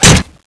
thanatos3_wood2.wav